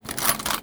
R - Foley 105.wav